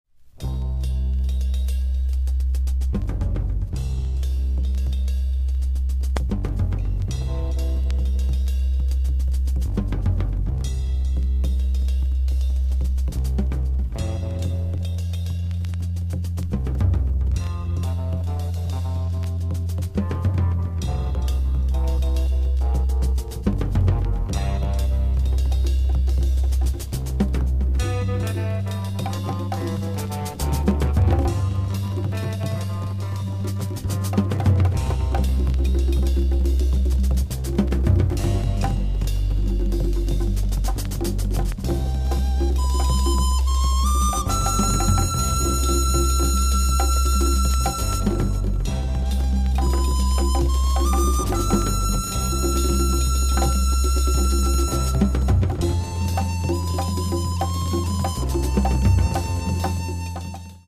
(注) 原盤の状態によりノイズが入っています。